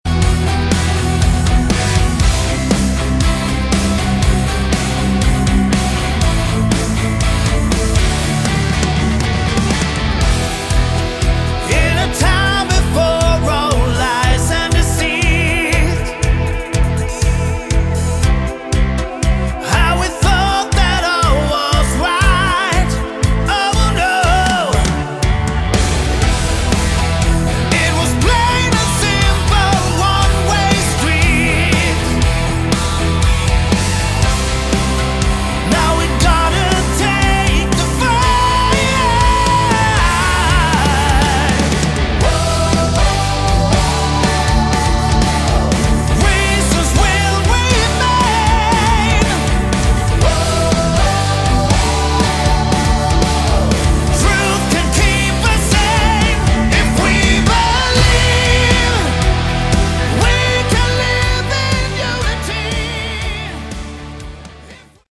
Category: Melodic Hard Rock
vocals
guitars
keyboards
bass
drums